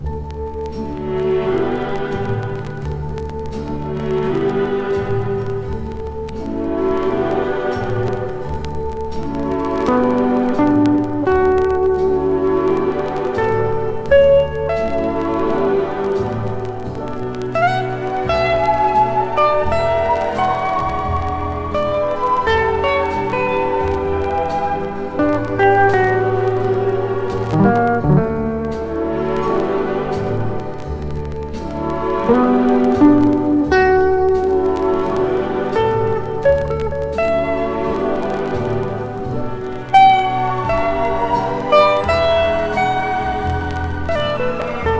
タイトル通りのハリウッド録音。
きめ細かく行き届いたギタープレイと、素晴らしいストリングスアレンジで非日常の夢見を与えてくれます。
Pop, Jazz, Country　USA　12inchレコード　33rpm　Stereo